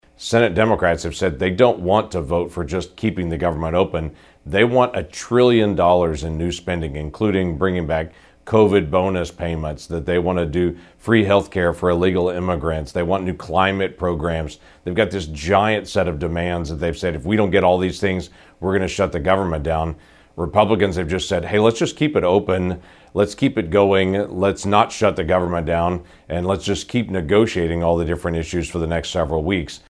Oklahoma's senior senator, James Lankford, issued a video statement Tuesday morning on the looming federal government shutdown.